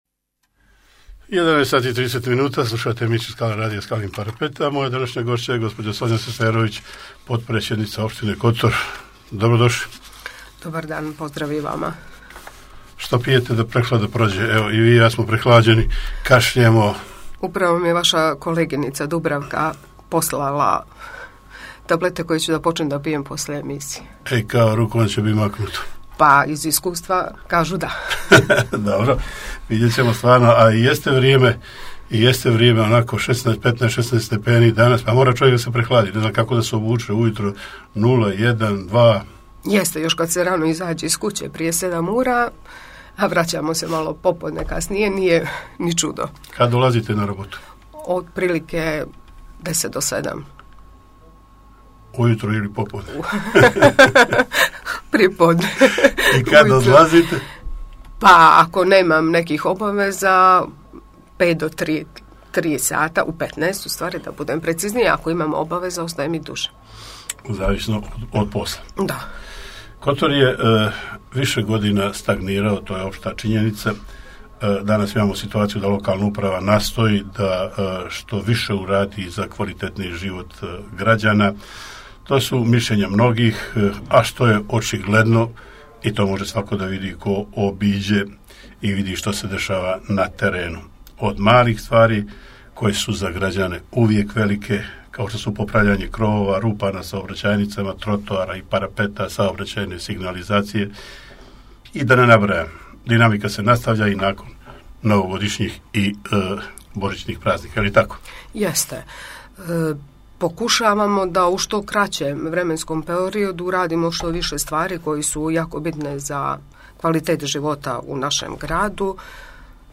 Gošća emisije potpredsjednica opštine Kotor Sonja Seferović